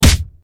punch3.ogg